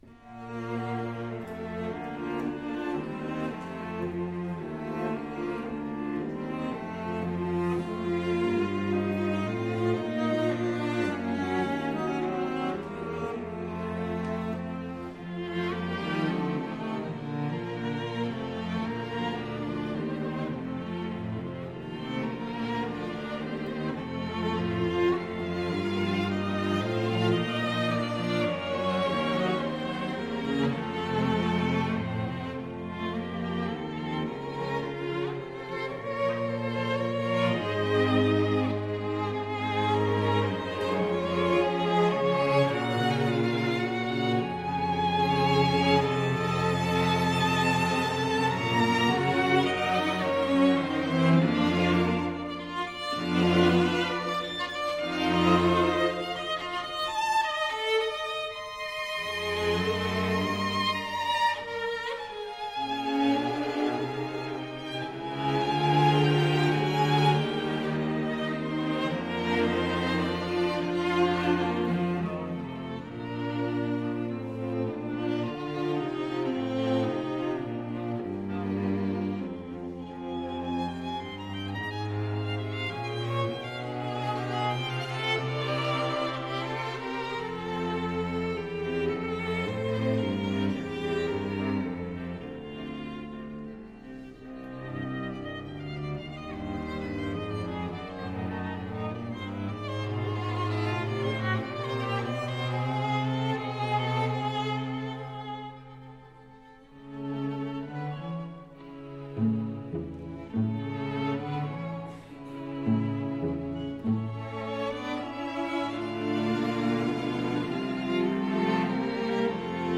String Sextet
Style: Classical
Audio: Boston - Isabella Stewart Gardner Museum
Audio: Musicians from Marlboro (ensemble)
string-sextet-1-op-18.mp3